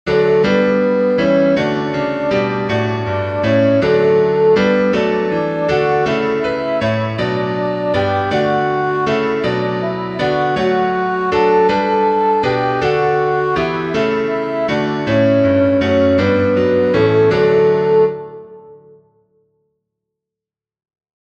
Alto
puer_nobis-alto.mp3